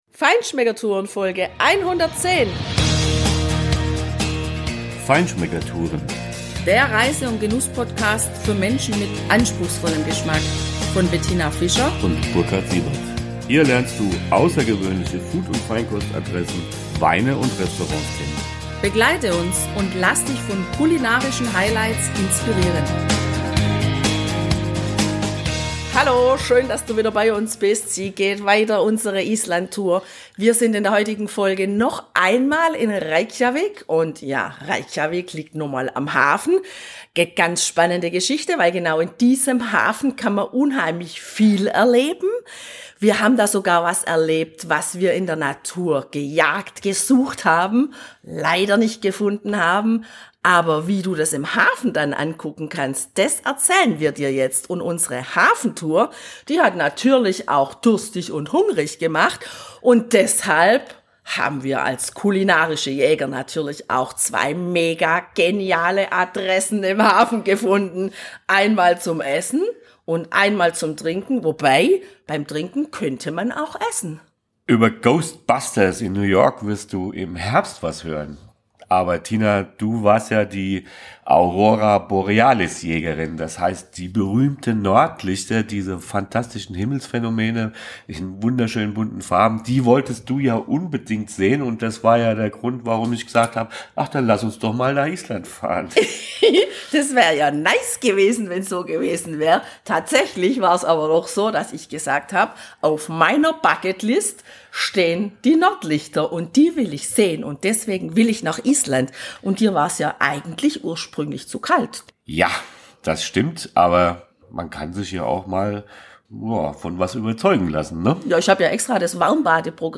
Ab Minute 19:28 hörst du unsere Live-Beschreibung von einem super Menü bei der „Fish Company“!